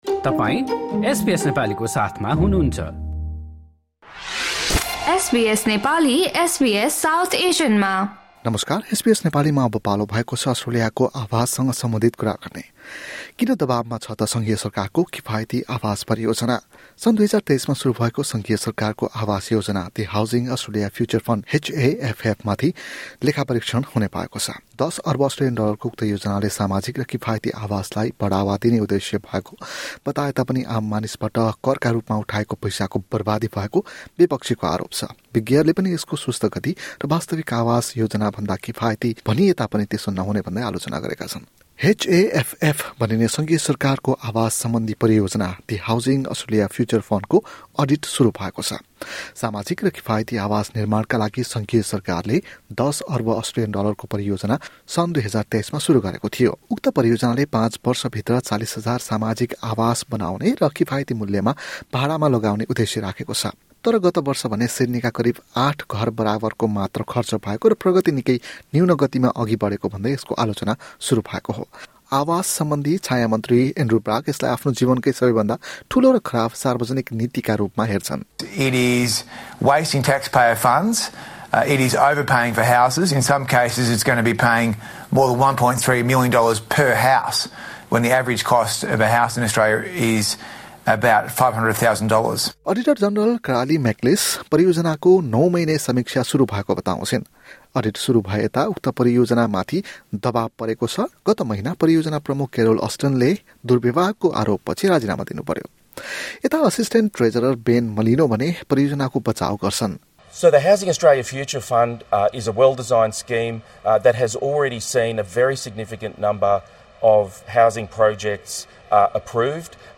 एक रिपोर्ट।